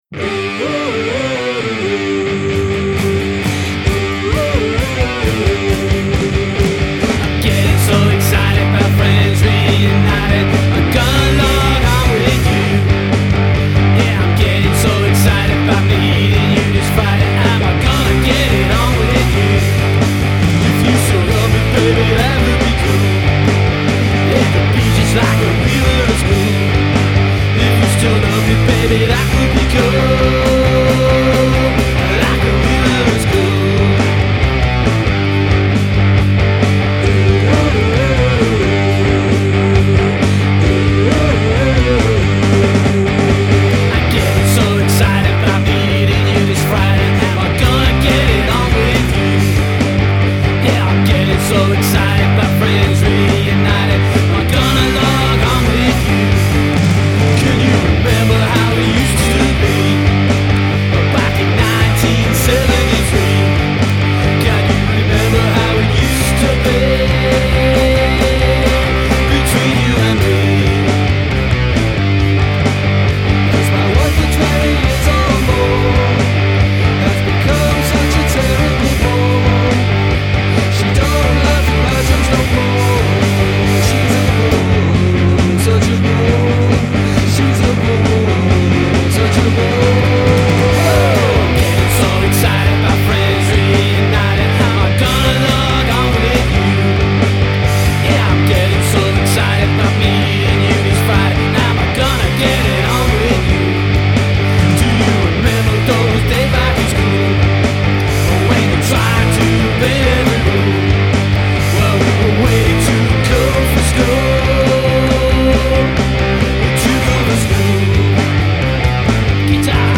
This is another observational sort of song and not a judgemental one, though it does sound a note of warning at the end I guess, where I had this image of an old girlfriend remembering the sixteen old boyfriend she used to know and then meeting the wrinkly, balding middle-aged man I, I mean he had become! When I was first working on this one I was trying to play it in a 'Bo Diddily' style but I couldn't keep the rhythm so it was back to sub 'Ramones' style, I think it sounds pretty good though - very 'college rock'.
I sing the chorus's and some other backing vocals.